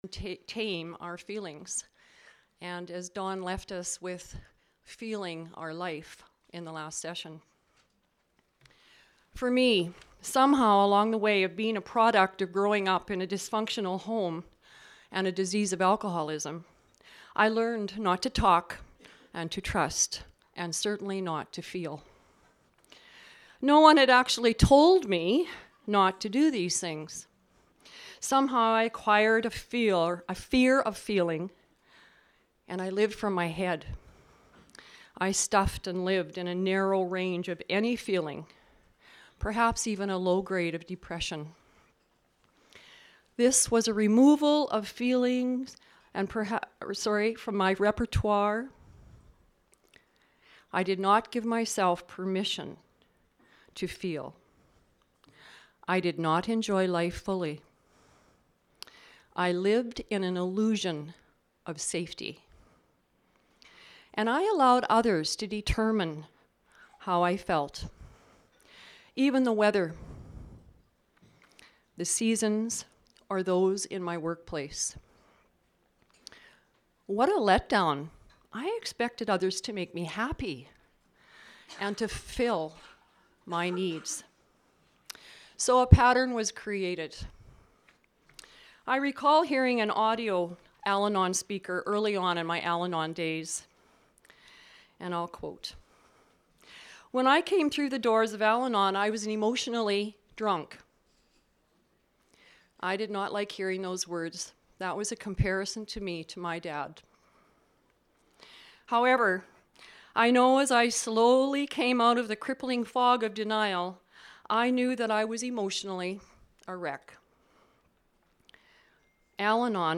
Every year (almost) we hold a recovery event with first-class speakers from Al-Anon and Alcoholics Anonymous, along with workshops, panels, meetings and fellowship.
Name,Claim and Tame Workshop.MP3